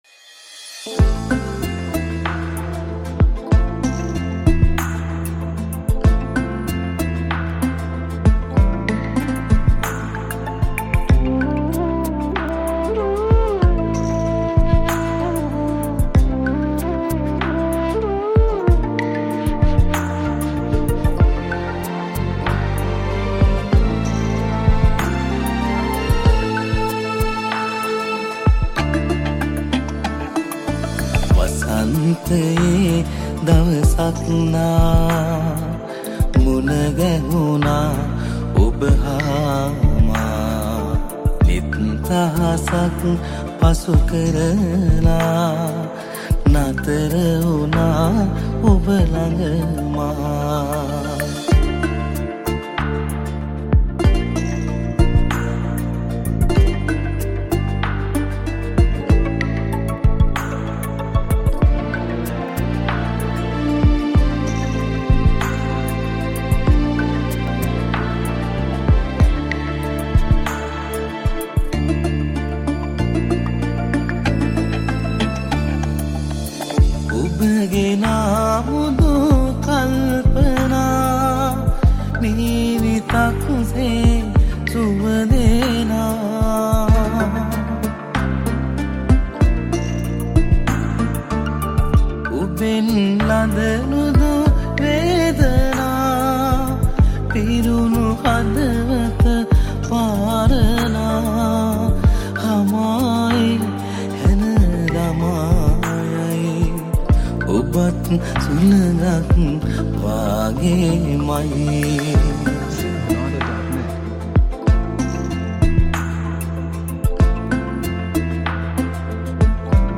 Category: Teledrama Song